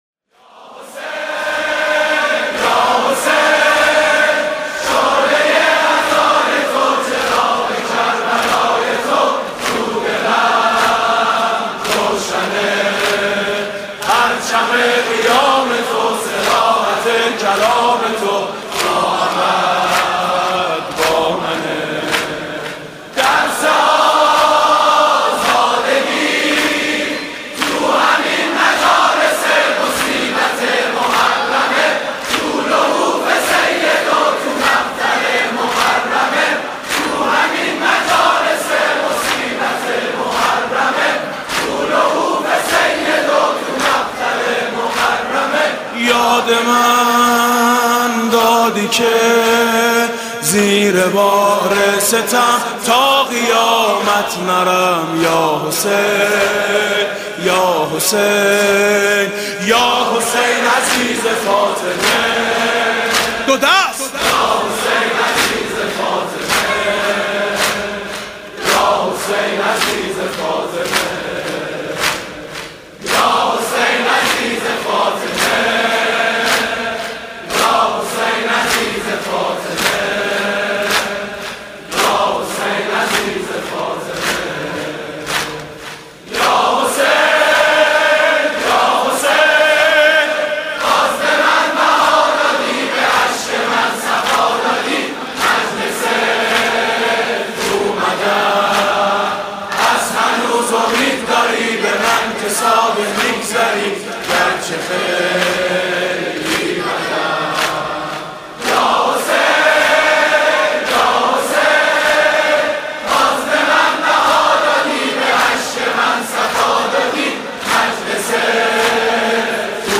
حاج میثم مطیعی
شب تاسوعا محرم 96 - هیئت میثاق - دم - یا حسین یا حسین من کجا لیاقت اقامه عزای تو